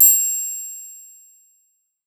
SIZZLE-TRIANGLE.wav